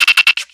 Cri de Grainipiot dans Pokémon X et Y.